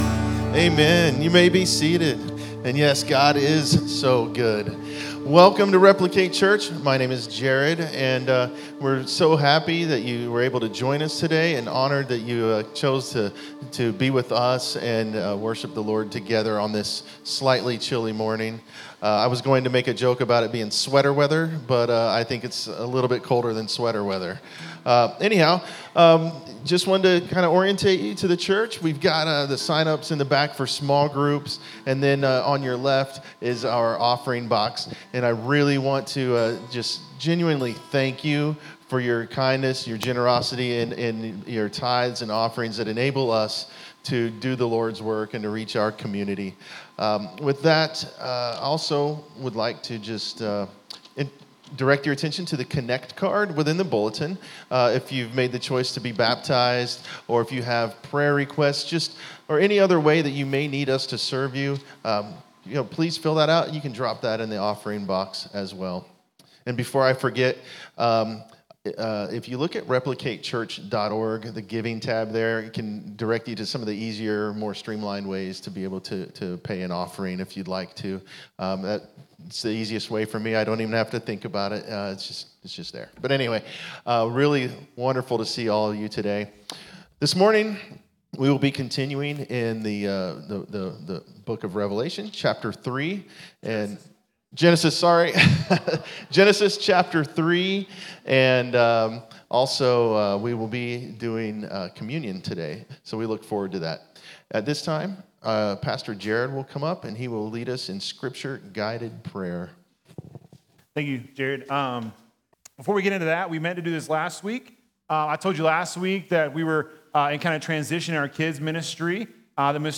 Sermons | Replicate Church